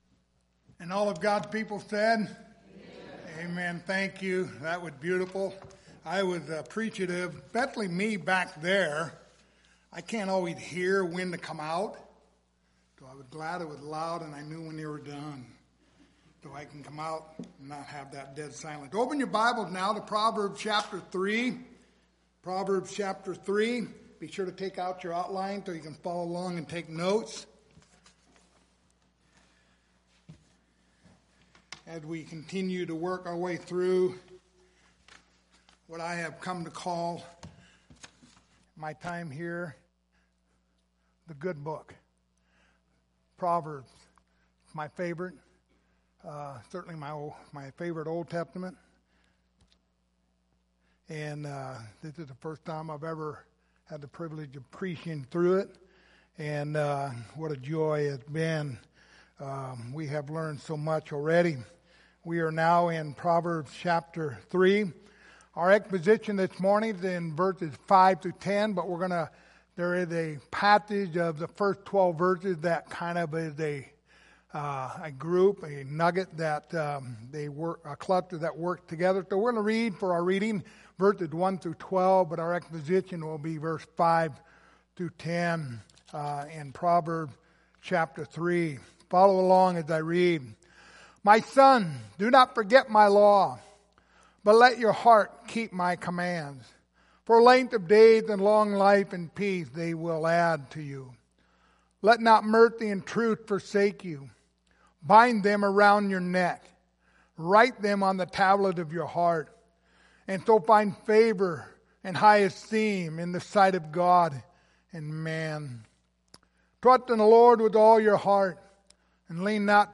The Book of Proverbs Passage: Proverbs 3:5-10 Service Type: Sunday Morning Topics